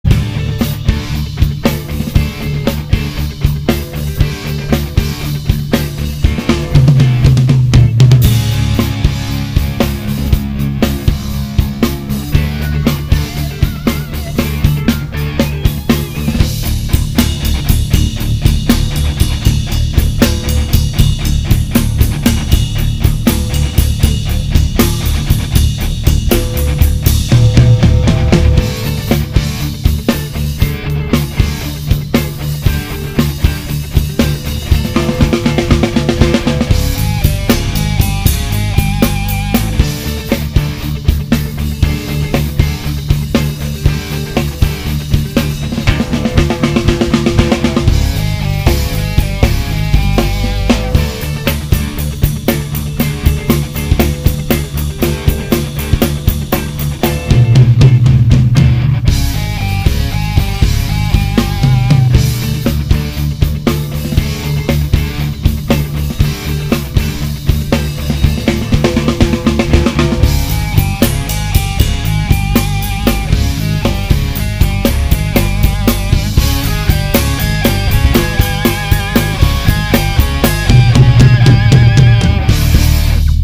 Náhrávky nejsou normalizovány, nejsou přidány žádné efekty.
DÍL TŘETÍ k tomu všemu ještě já na zkreslenou kytaru,